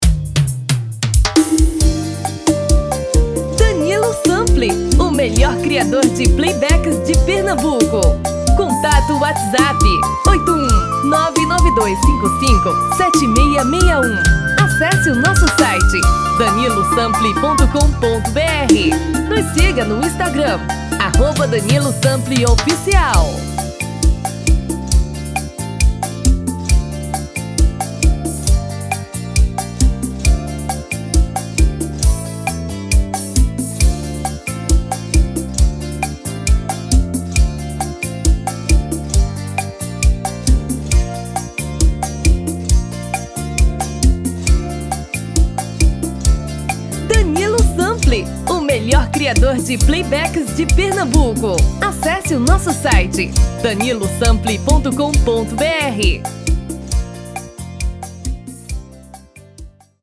TIPO: Pot-Pourri de 4 músicas sequenciadas
RITMO: Arrocha / Seresta
TOM: Feminino (Original)